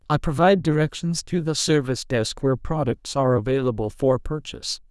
TTS_audio / PromptTTS++ /sample1 /Template2 /Condition /Customer /Emotion /surprised /unexpected.wav